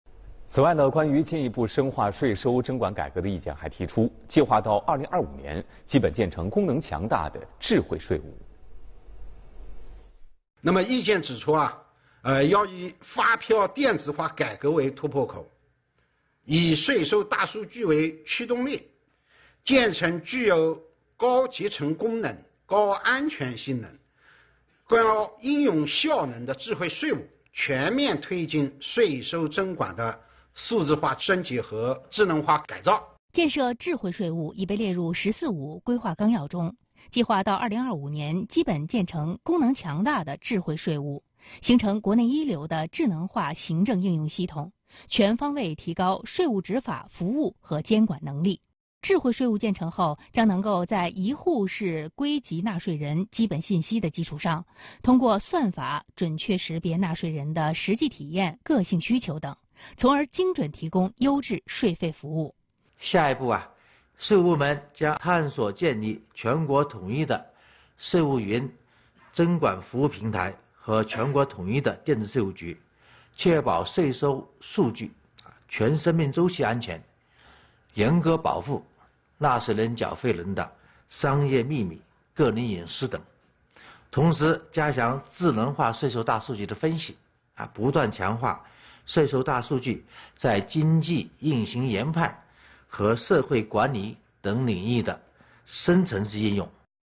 视频来源：央视《朝闻天下》